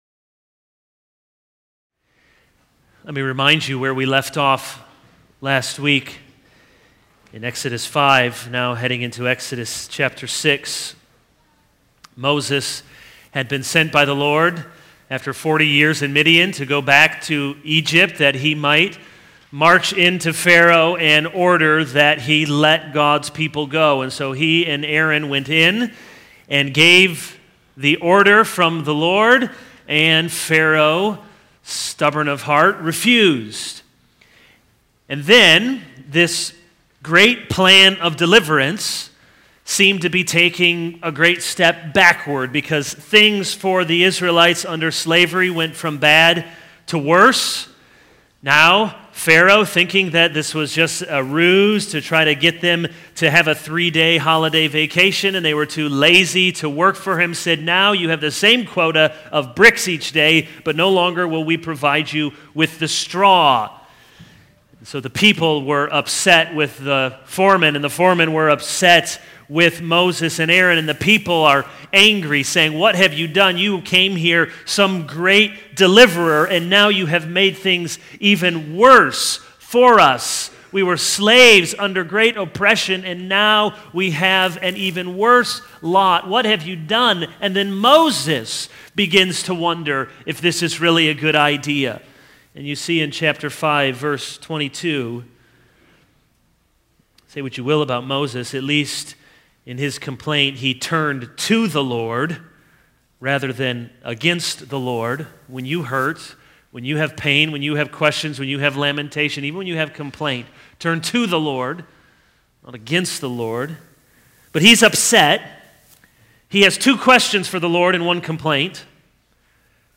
This is a sermon on Exodus 6:1-9.